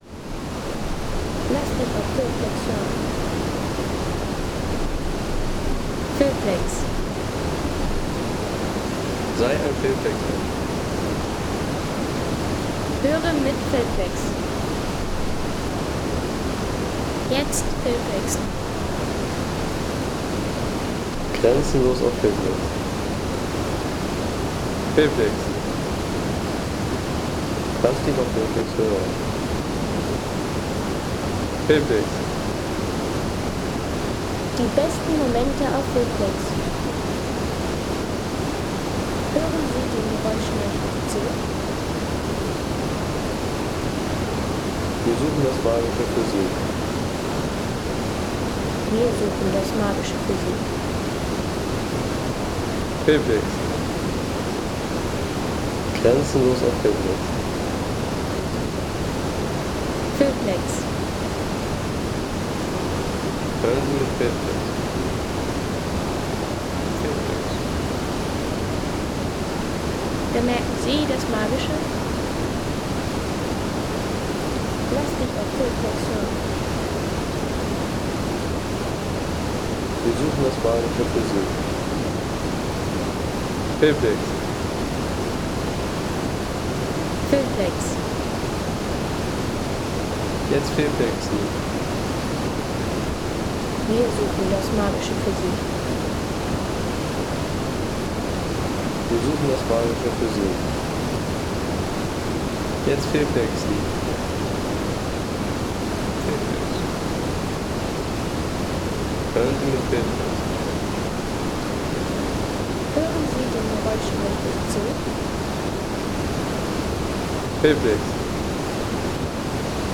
Umbalfälle - Hohe Tauern National Park
Landschaft - Wasserfälle